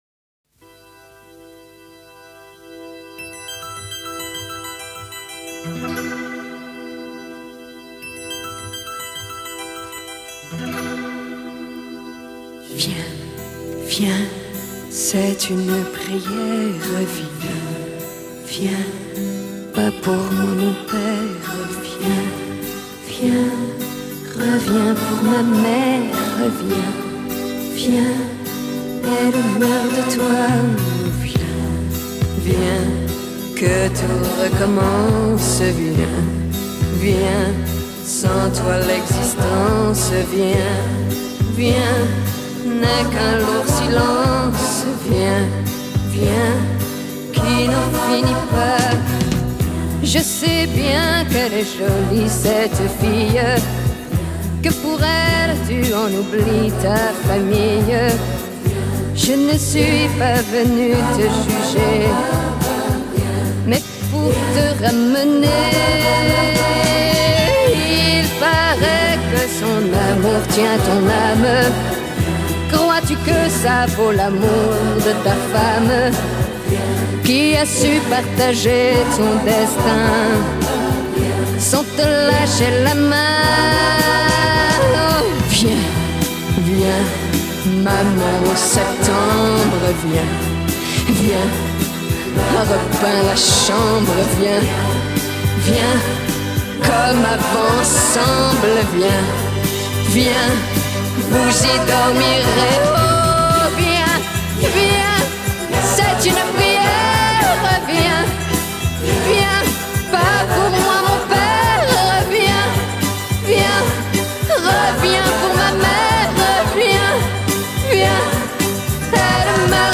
который сочетает элементы поп, шансона и французской эстрады